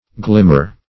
Glimmer \Glim"mer\, n.